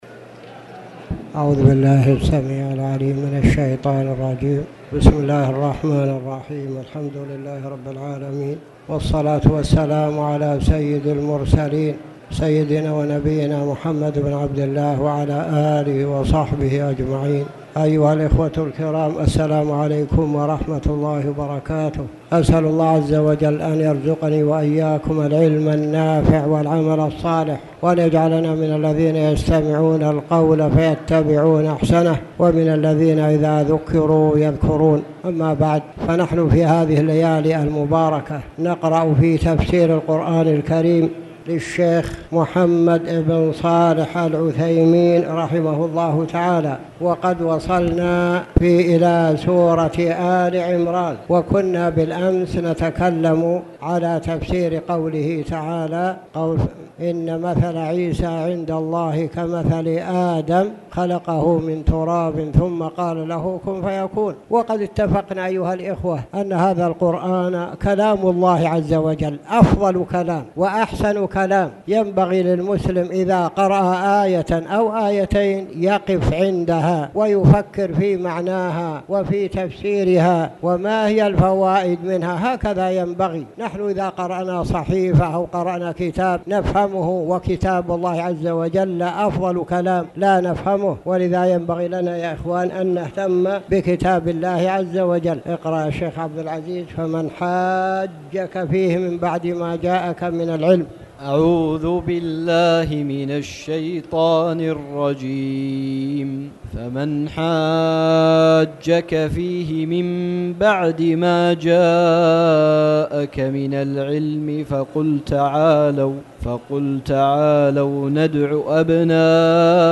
تاريخ النشر ٢ رمضان ١٤٣٨ هـ المكان: المسجد الحرام الشيخ